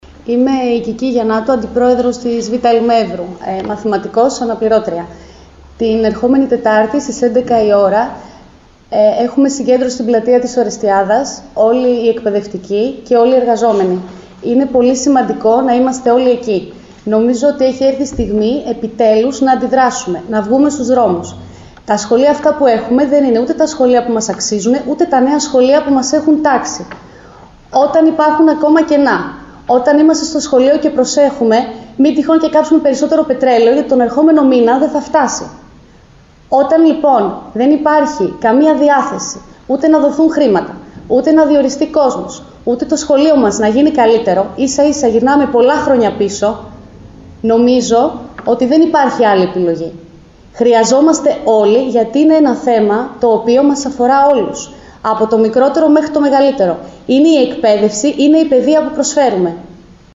Συνέντευξη τύπου